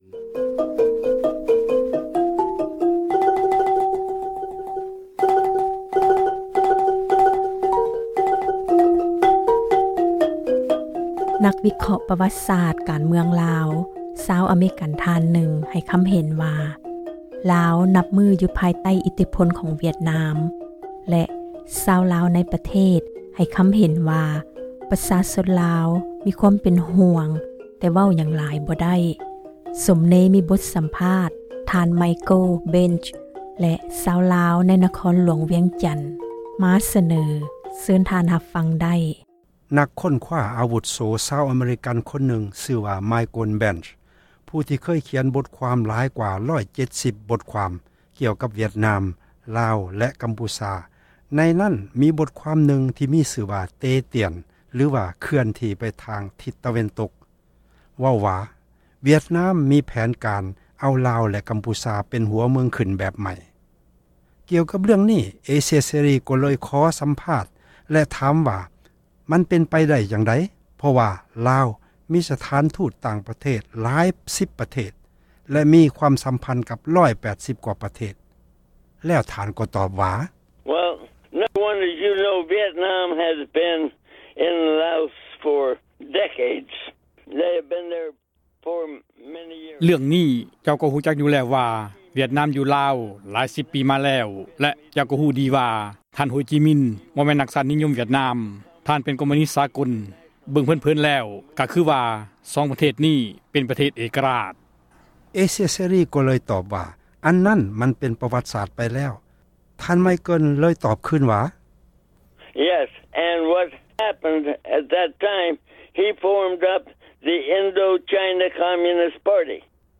ກ່ຽວກັບ ເຣື້ອງນີ້ ເອເຊັຽ ເສຣີ ກໍເລີຍ ຂໍ ສັມພາດ ແລະ ຖາມວ່າ ມັນເປັນ ໄປໄດ້ ຈັ່ງໃດ ເພາະວ່າ ລາວ ມີ ສະຖານທູດ ຕ່າງ ປະເທດ ຫຼາຍສິບ ປະເທດ ແລະ ມີຄວາມ ສັມພັນ ກັບ 180 ກວ່າ ປະເທດ ແລ້ວ ທ່ານ ກໍຕອບວ່າ: ເຊີນ ທ່ານ ຮັບຟັງ ການ ສັມພາດ ຕໍ່ໄປ ...